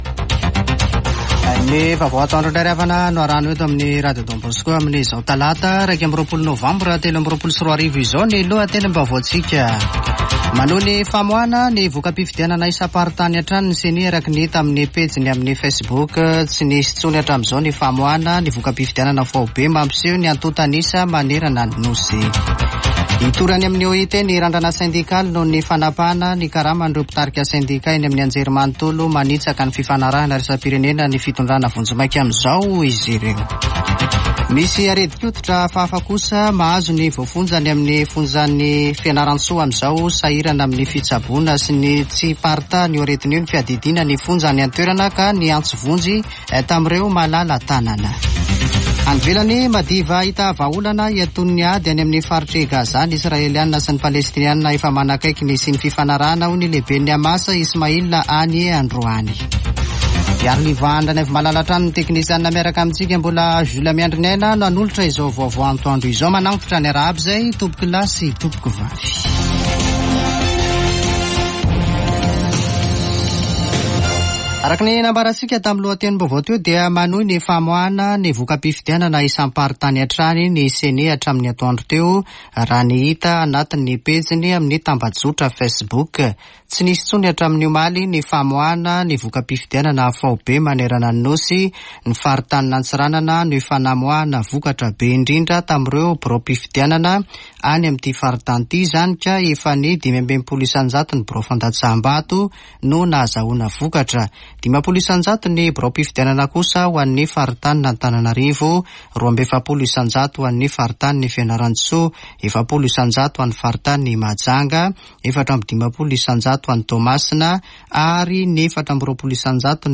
[Vaovao antoandro] Talata 21 nôvambra 2023